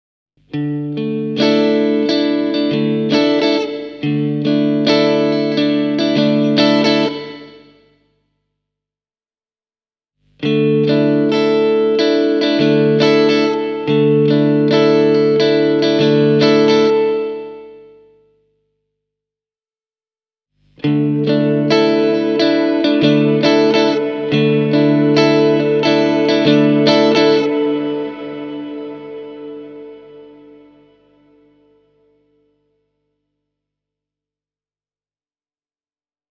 Room on huonetta (tai studiotilaa) muistuva kaikusoundi, kun taas Spring-vaihtoehto on digitaalinen näköispainos jousikaiusta. Shimmer on ShimVerbin erikoiskaiku, jossa tuodaan kaikuun hieman metallista hohdetta lisäämällä signaaliin kvintin verran ylöspäin nostettua ääntä.
Ainakin omiin korviini Room on pikkusen liian honottava, eikä Spring-kaiusta löydy niin selvää jousimaista klangia ja pärskettä kuin odotin.
Aloitan äänipätkässä Room-kaiulta: